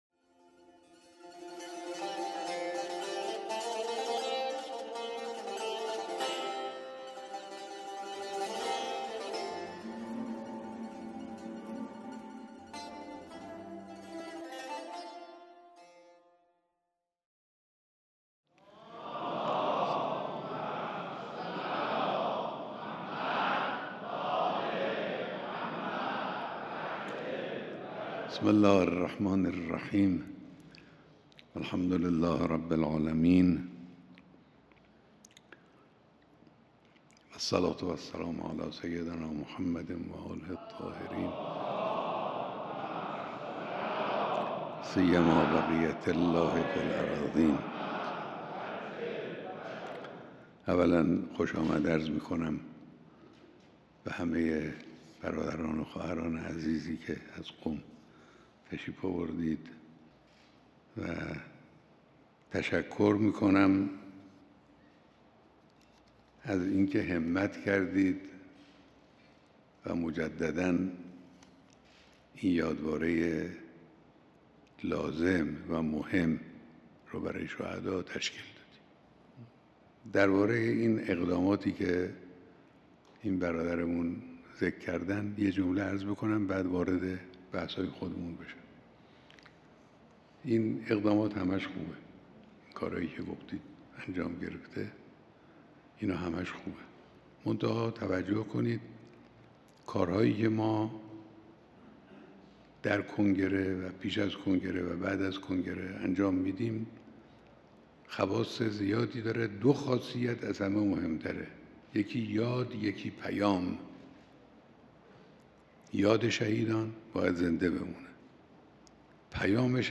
بیانات در دیدار دست‌اندرکاران کنگره بزرگداشت شهدای قم